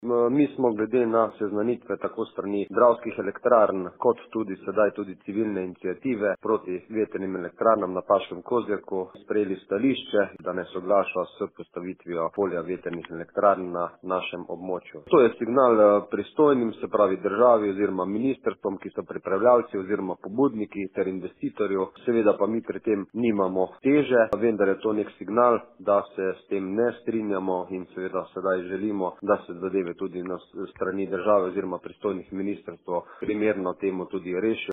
Župan Andraž Pogorevc je za Radio Rogla in časopis NOVICE ob tem povedal: